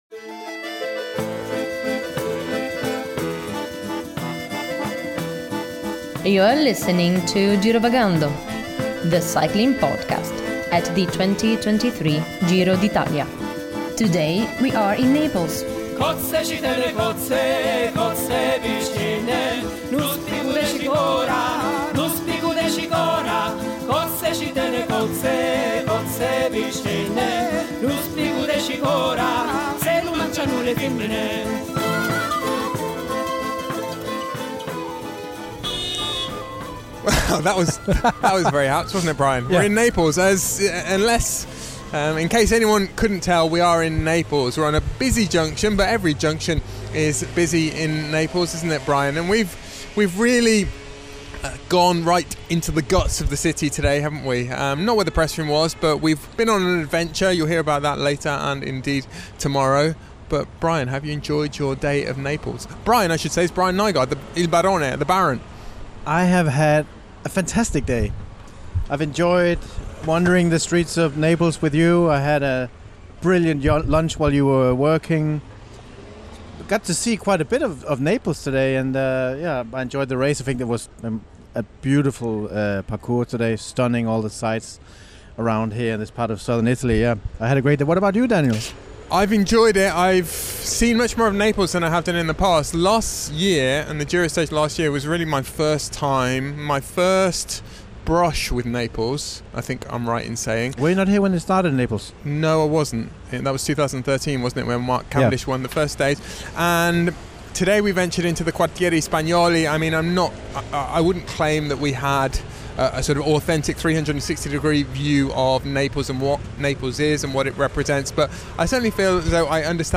we're on a busy junction